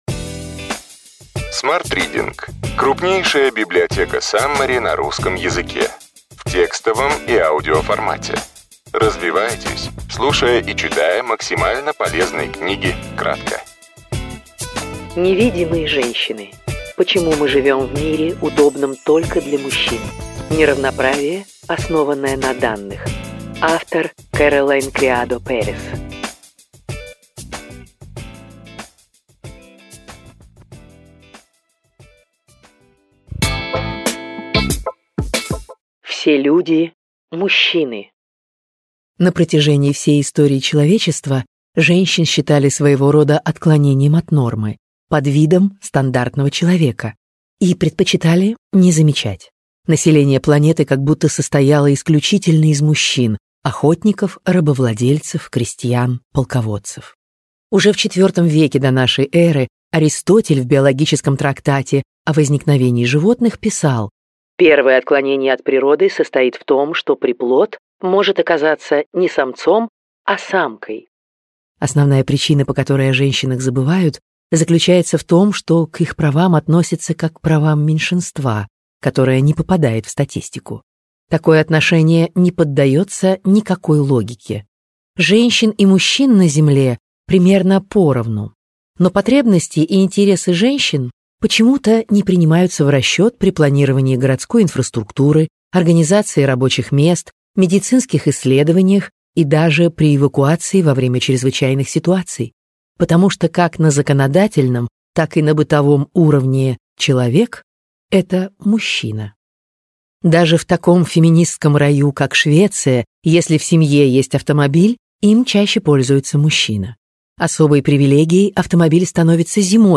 Аудиокнига Невидимые женщины. Почему мы живем в мире, удобном только для мужчин: неравноправие, основанное на данных.